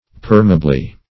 permeably - definition of permeably - synonyms, pronunciation, spelling from Free Dictionary Search Result for " permeably" : The Collaborative International Dictionary of English v.0.48: Permeably \Per"me*a*bly\, adv.